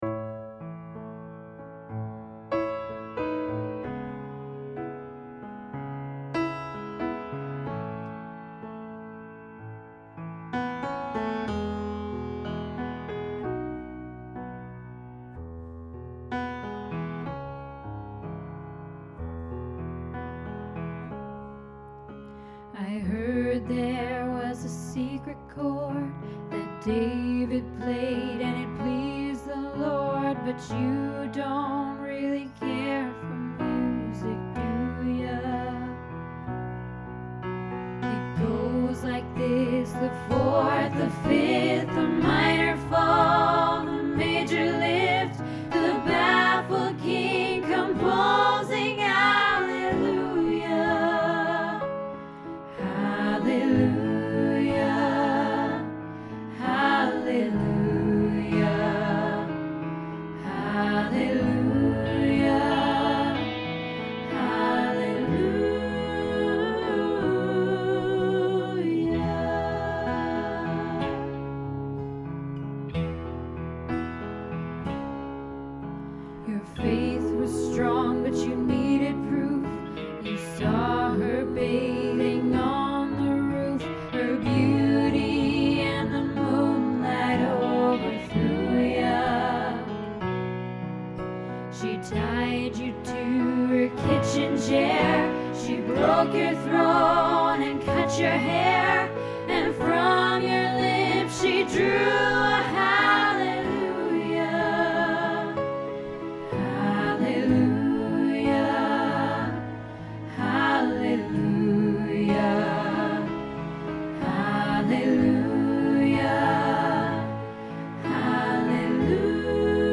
Hallelujah Practice